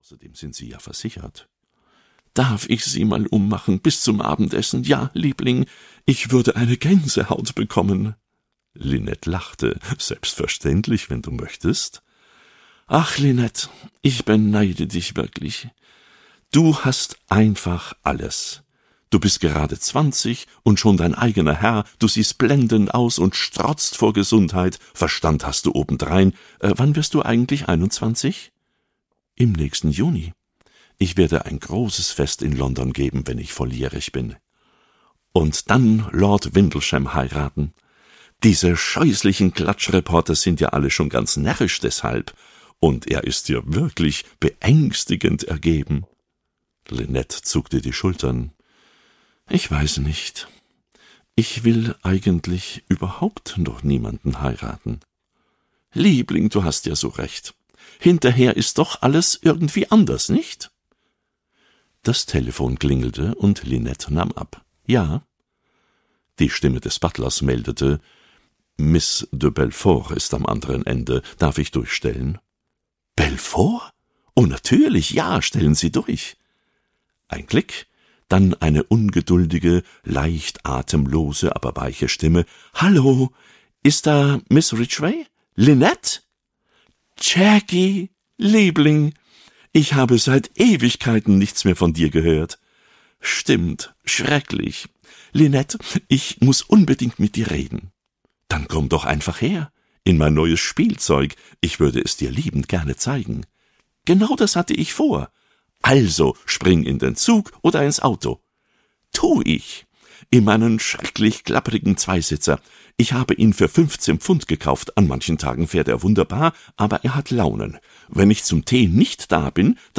Tod auf dem Nil (DE) audiokniha
Ukázka z knihy
• InterpretGerd Anthoff